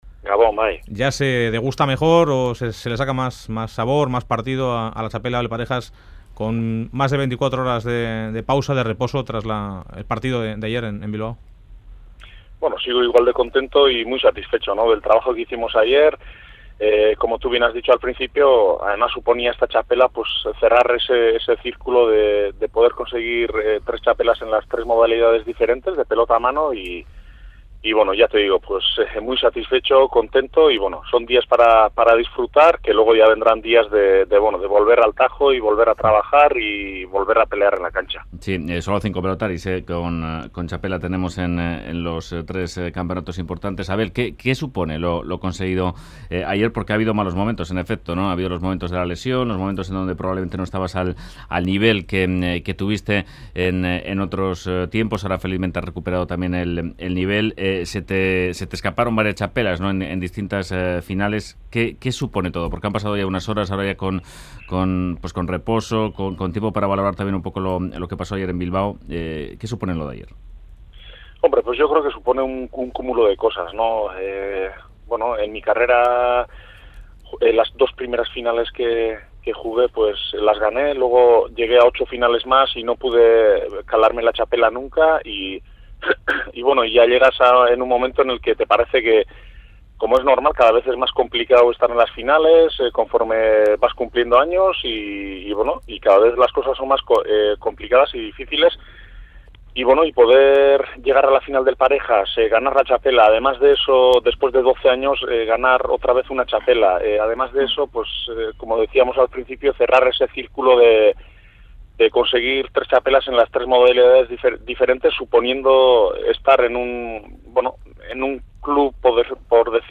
Fuera de Juego recibe al pelotari Abel Barriola